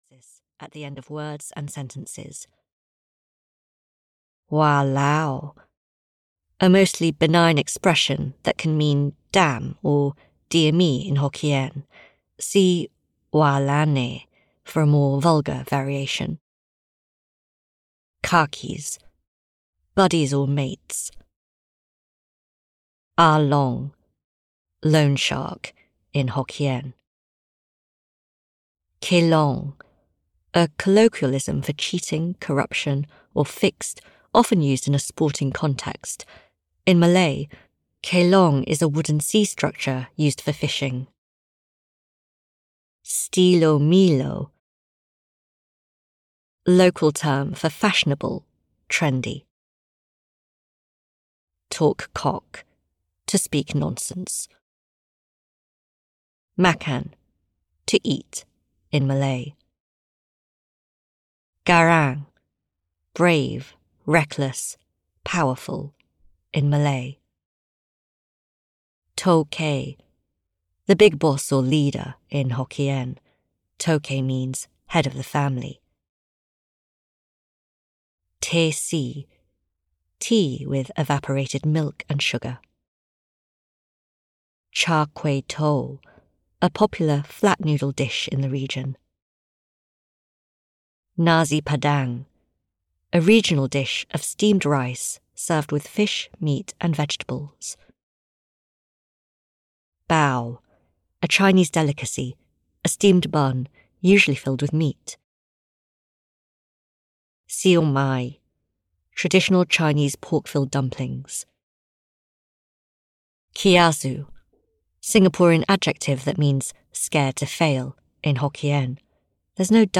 Marina Bay Sins (EN) audiokniha
Ukázka z knihy